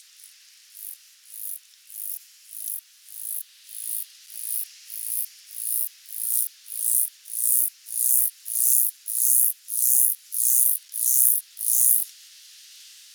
4. Northern Flicker (Colaptes auratus)
Call: A loud, ringing “kleer” and series of “wik-wik-wik.”